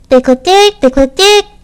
Audio / SE / Cries / DIGLETT.mp3